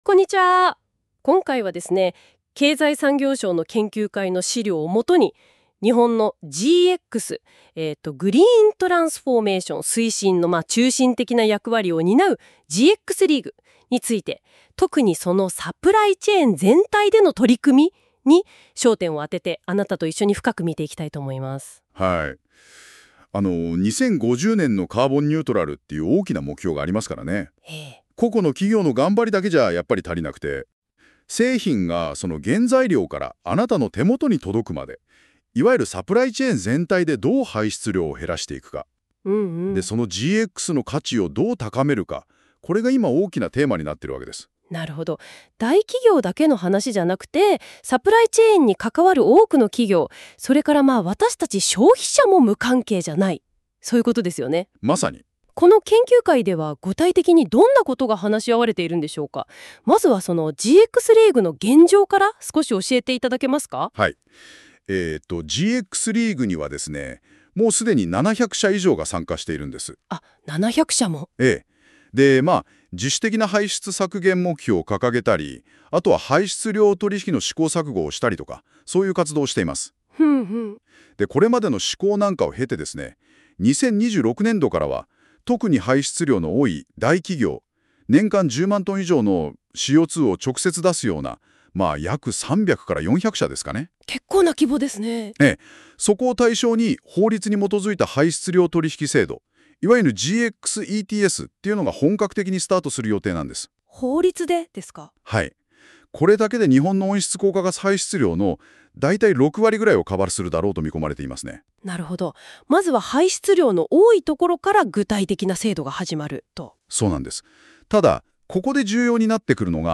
今回は、2025年5月23日に経済産業省が公開した「第1回 GXリーグにおけるサプライチェーンでの取組のあり方に関する研究会」の事務局説明資料について、NotebookLMが作成した音声解説をご用意しました！